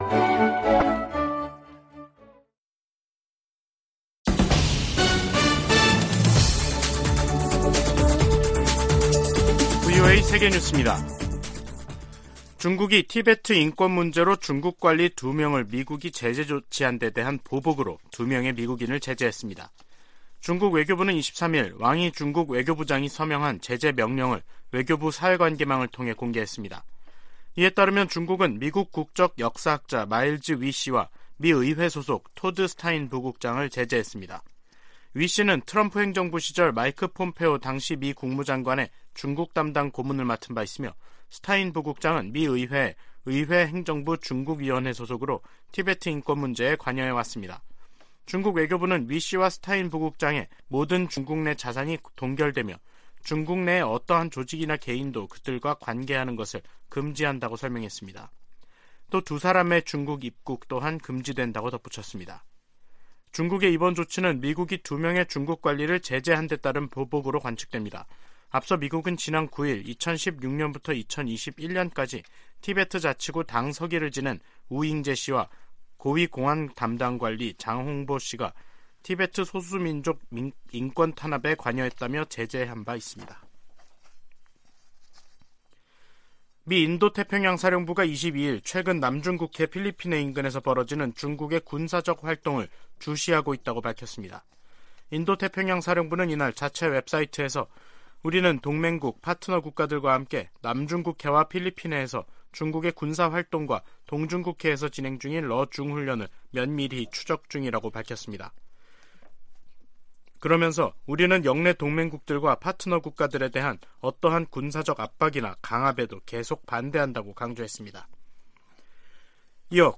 VOA 한국어 간판 뉴스 프로그램 '뉴스 투데이', 2022년 12월 23일 3부 방송입니다. 북한이 정찰위성 시험이라며 탄도미사일을 발사한 지 닷새만에 또 다시 탄도미사일을 발사했습니다. 미국 백악관은 러시아의 우크라이나 침공을 지원하는 현지 용병업체에 북한이 로켓과 미사일을 전달했다며, 북한-러시아 간 무기 거래 사실을 확인했습니다.